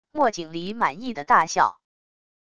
墨景黎满意的大笑wav音频